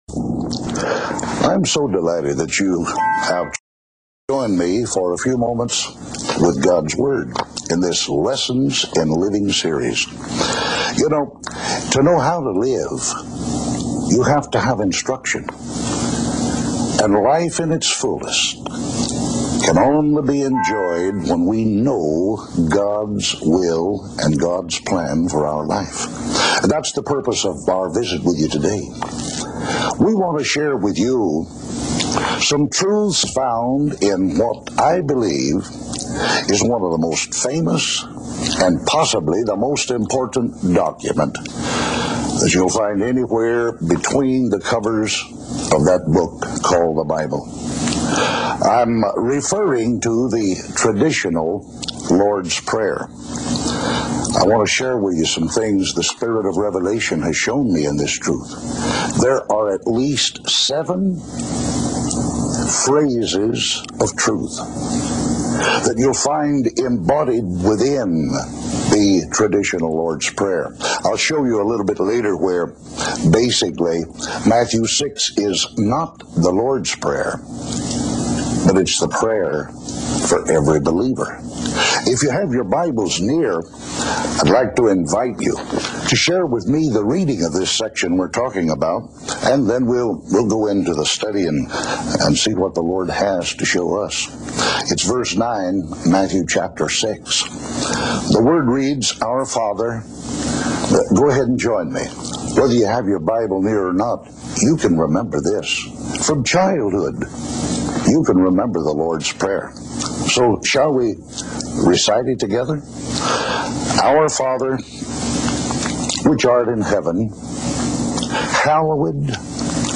Lessons for Living – Television Teaching